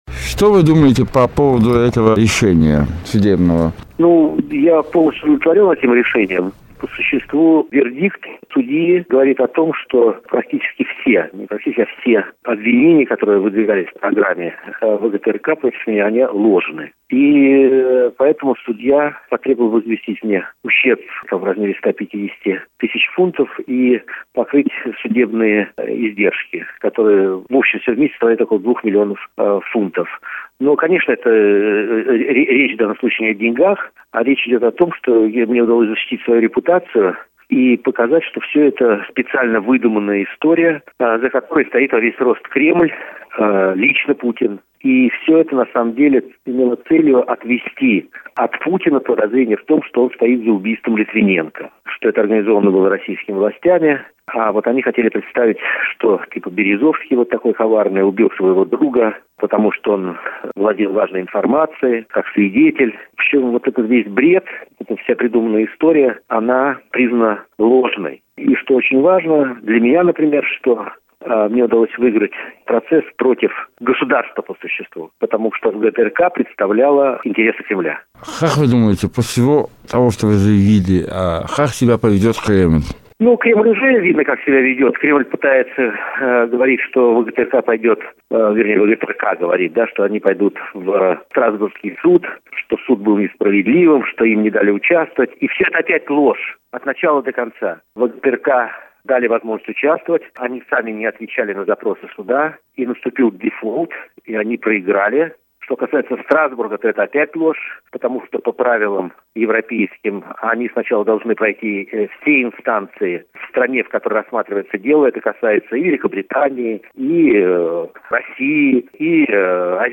Проживающий сейчас в Лондоне предприниматель Борис Березовский, бывший с 29 апреля 1998 года по 4 марта 1999 года председателем Исполнительного секретариата СНГ, дал эксклюзивное интервью РадиоАзадлыг